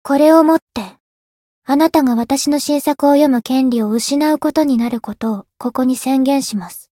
灵魂潮汐-爱莉莎-互动-厌恶的反馈.ogg